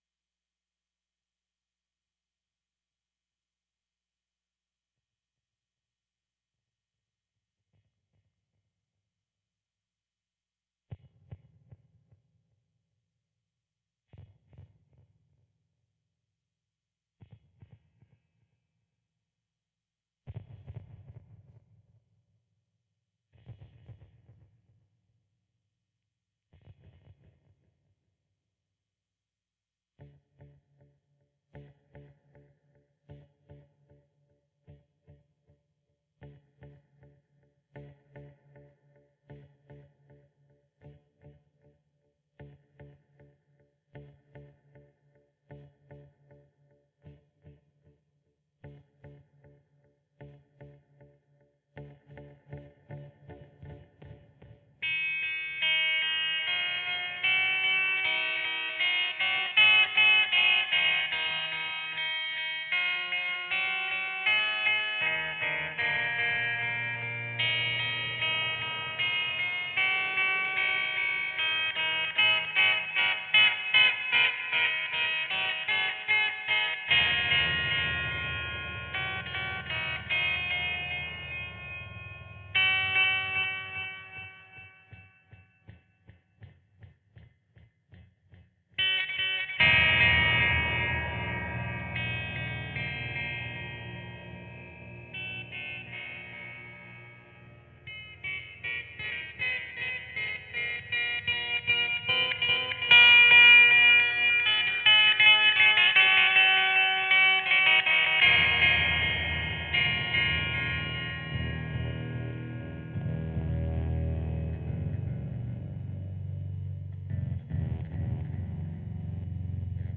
Experimental guitar song.
Soft, comes in slowly.